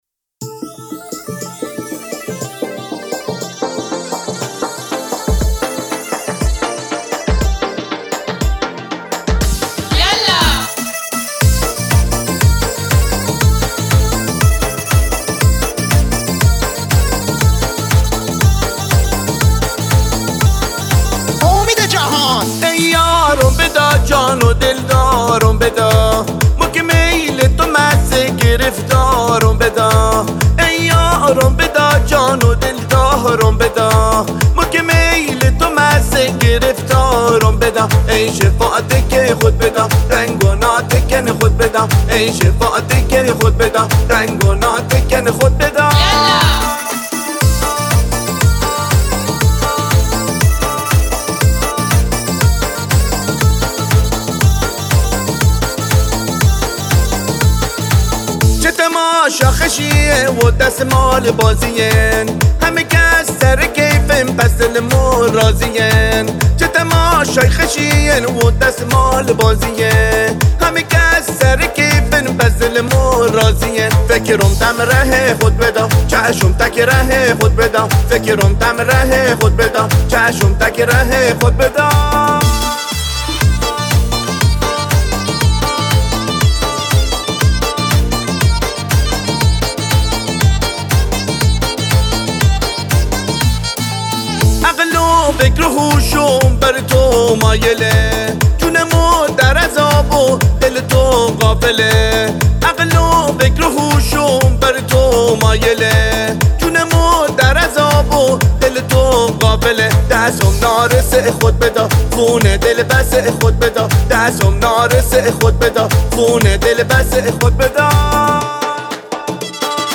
اهنگ بندری